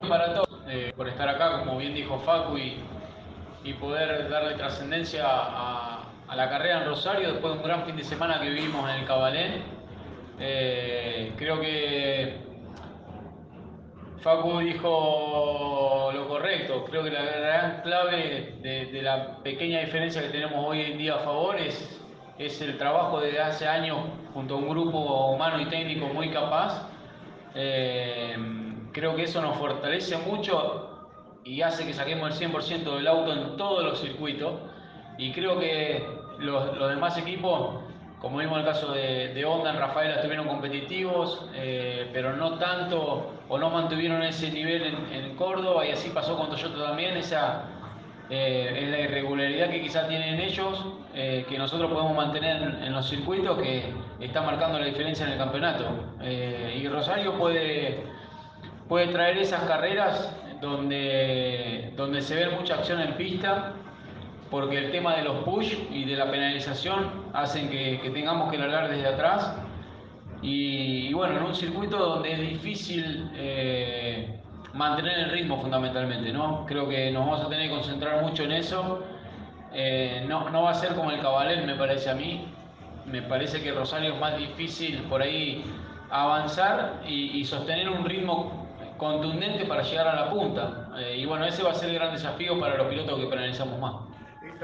El campeón de la categoría estuvo presente en la conferencia de prensa en Rosario y manifestaba la posibilidad de estar nuevamente en el autódromo «Juan Manuel Fangio» de Rosario.